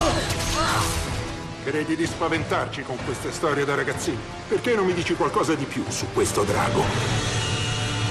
da un trailer cinematografico.